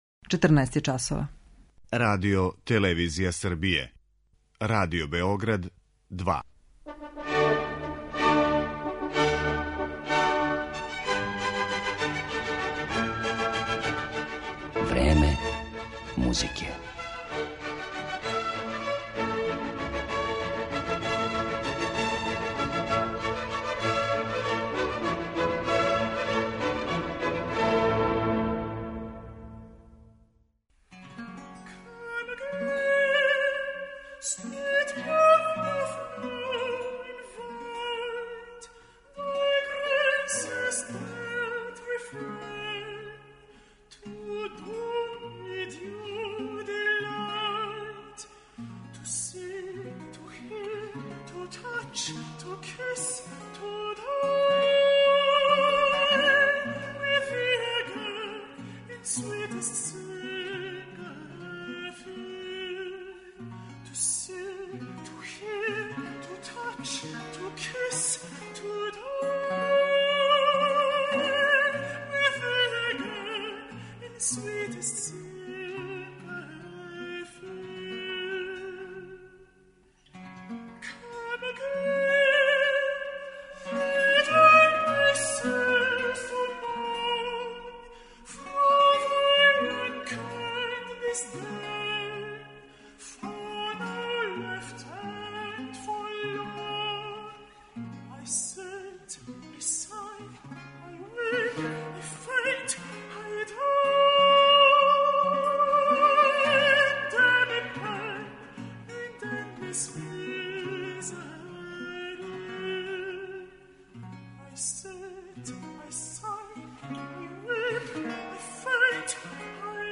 Емисија је посвећена румунском контратенору Валеру Сабадусу